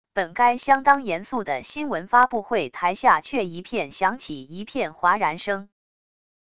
本该相当严肃的新闻发布会台下却一片响起一片哗然声wav音频